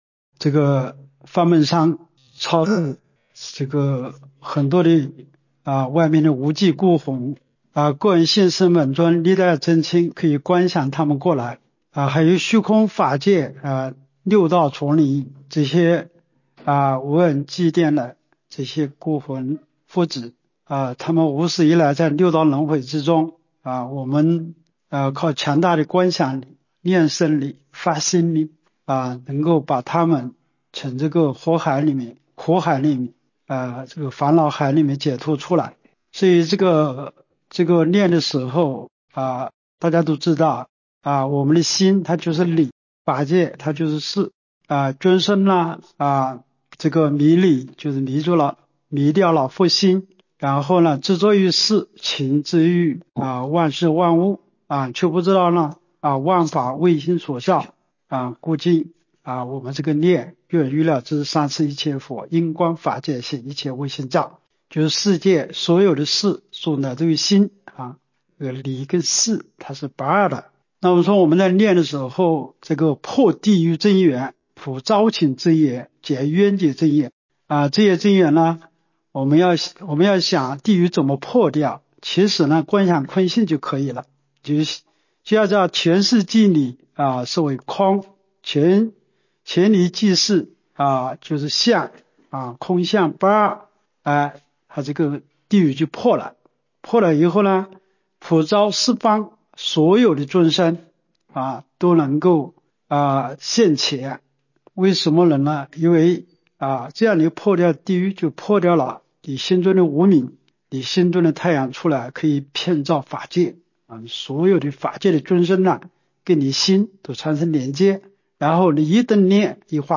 蒙山开示